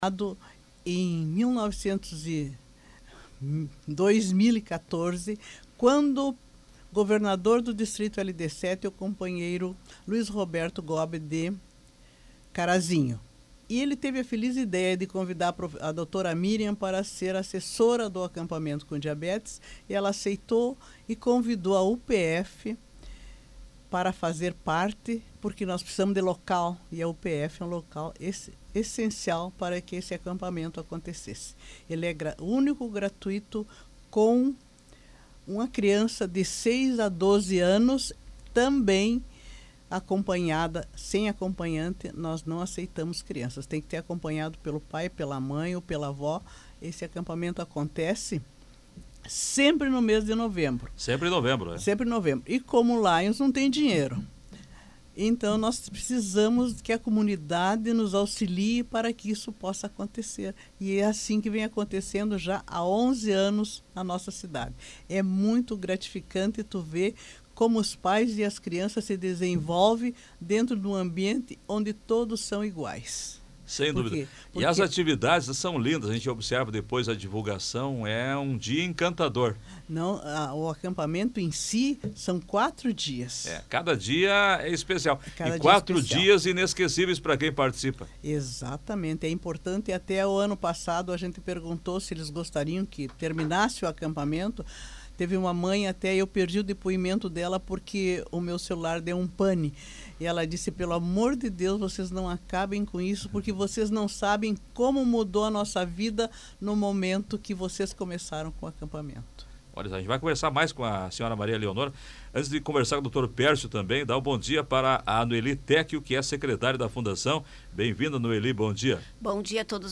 Para apresentar os detalhes, a equipe da organização esteve presente no programa Comando Popular, da Rádio Planalto News (92.1).